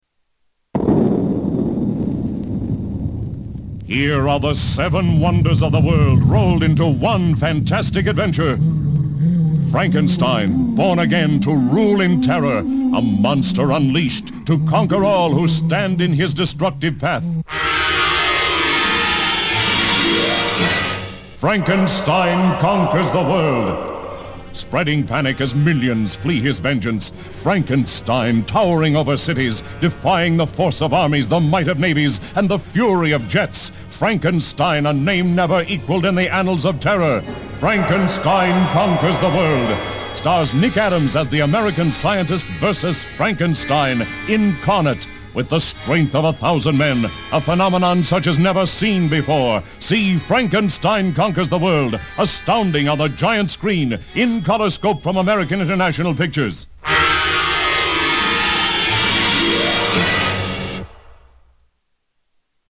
Radio Spots!
The spots are really good and the announcer brings excitement to the offerings.